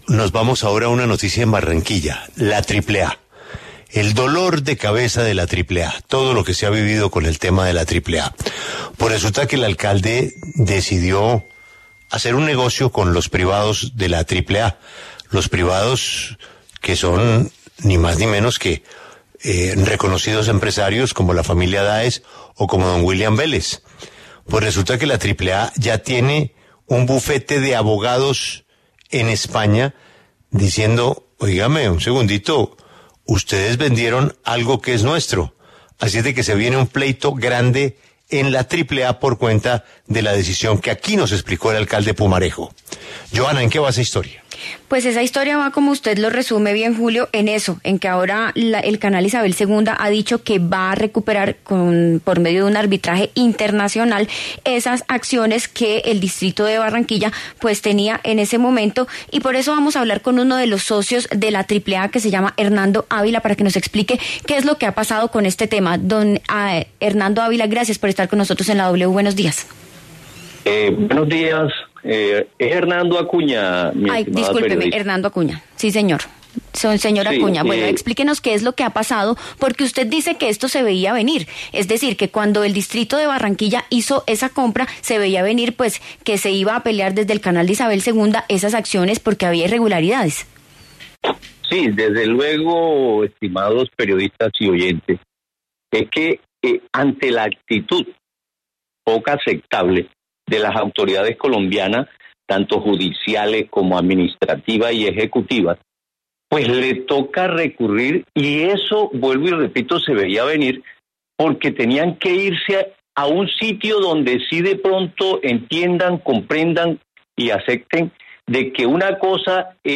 En diálogo con La W